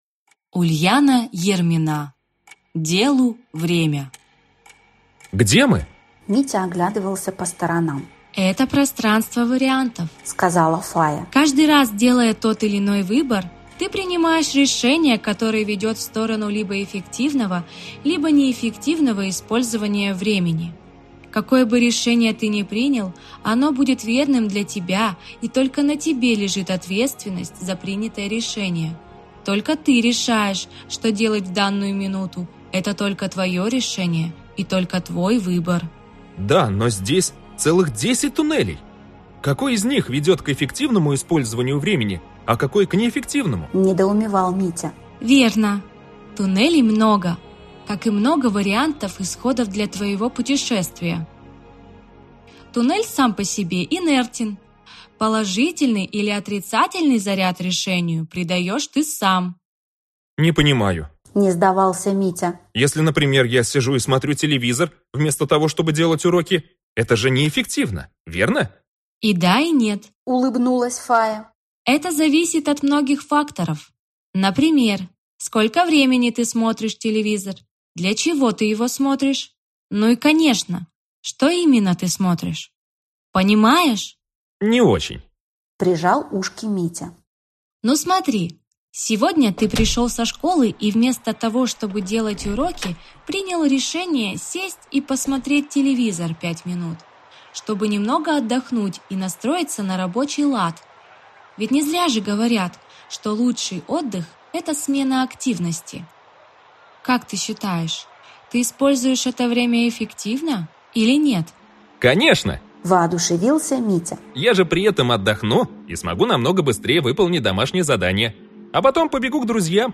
Аудиокнига Делу – время | Библиотека аудиокниг
Aудиокнига Делу – время Автор Ульяна Ермина Читает аудиокнигу Центр звукового дизайна ЗВУКАРНЯ.